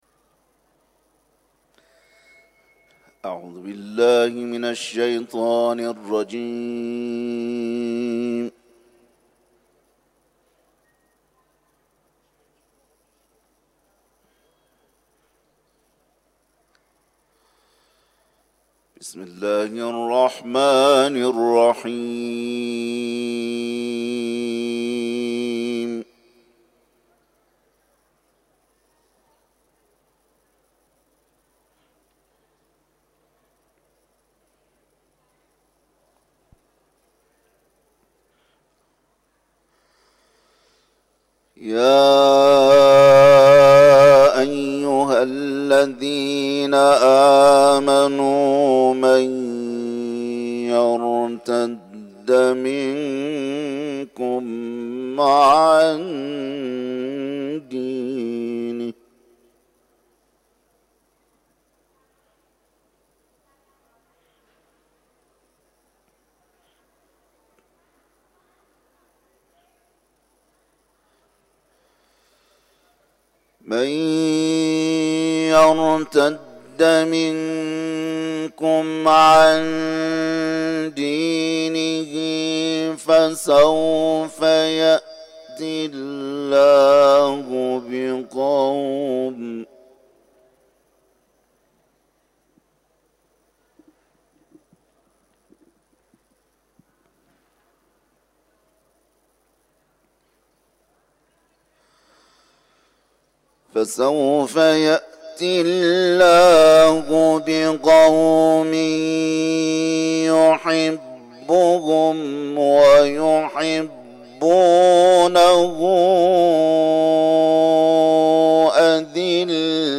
قاری بین‌المللی قرآن
تلاوت
سوره مائده ، حرم مطهر رضوی